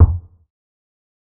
TC3Kick7.wav